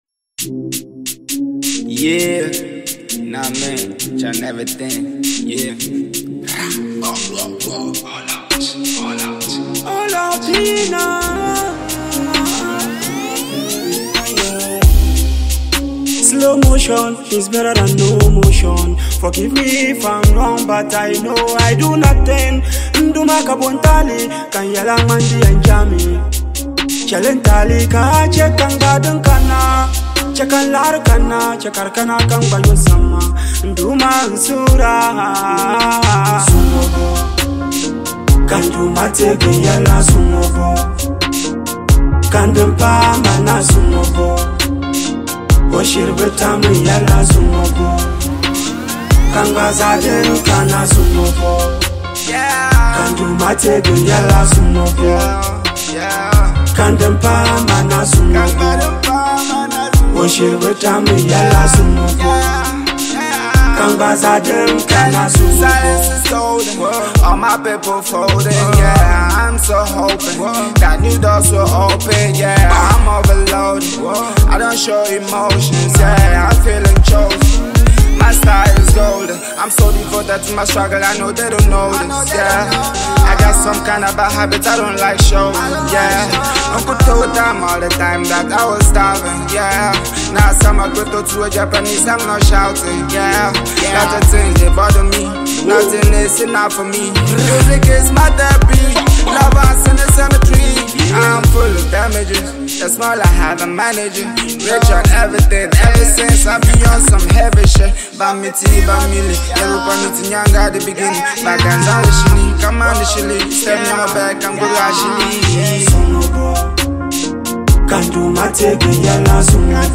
With its bold sound and infectious flow